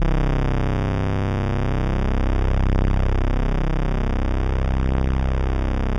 C2_trance_lead_1.wav